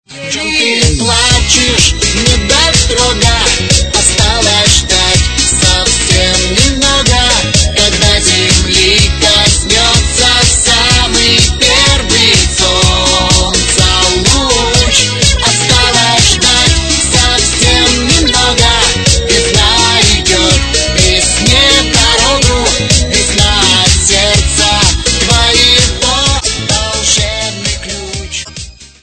Категория: Попса